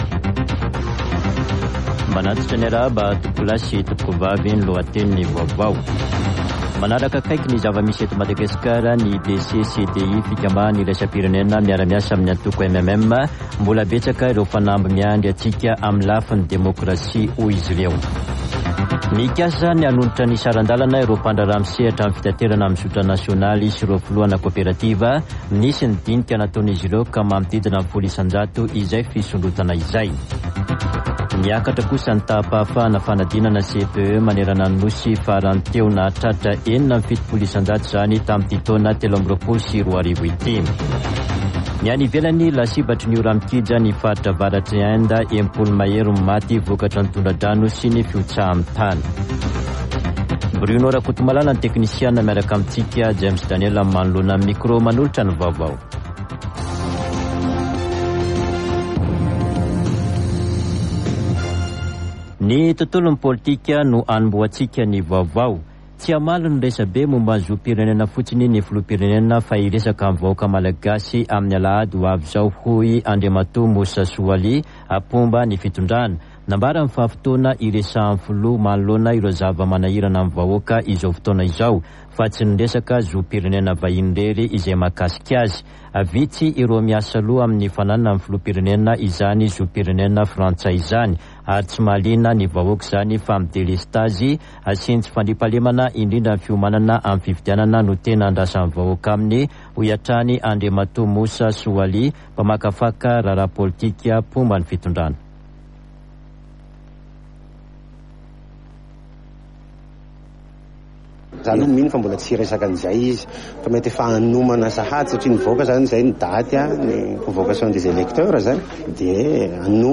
[Vaovao antoandro] Alakamisy 13 jolay 2023